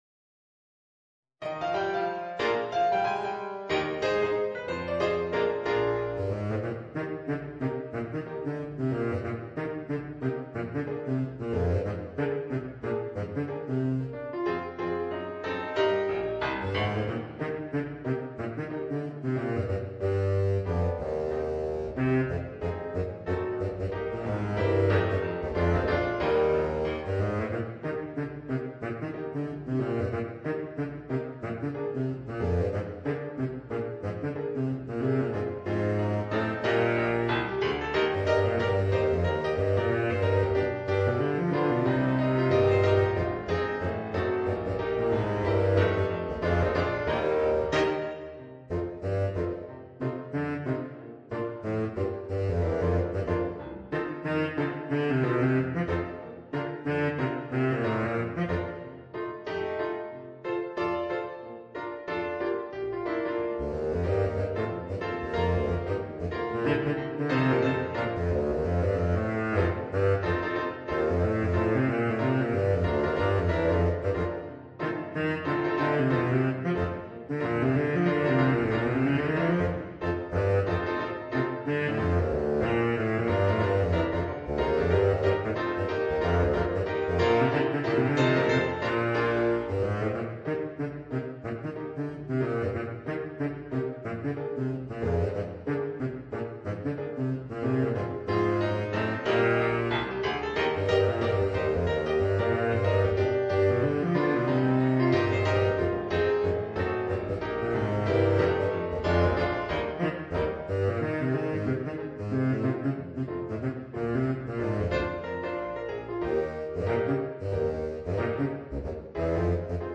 Voicing: Baritone Saxophone and Piano